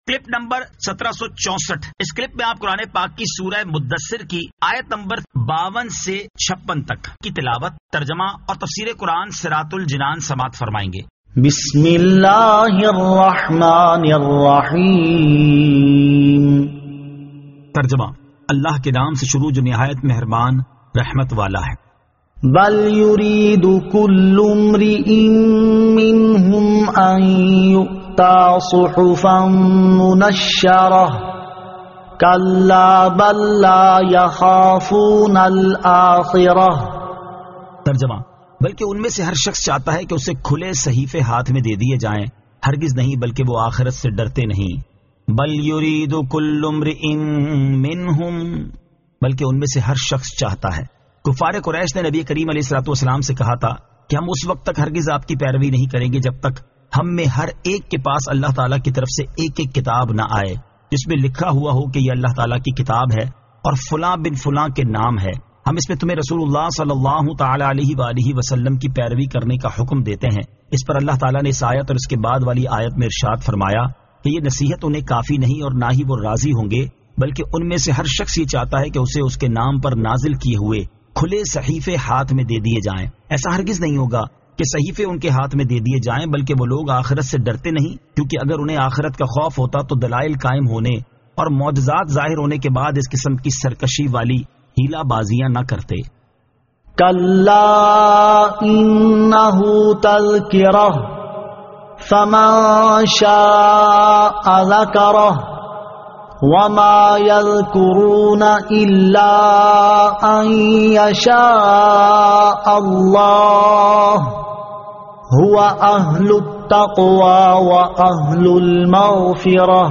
Surah Al-Muddaththir 52 To 56 Tilawat , Tarjama , Tafseer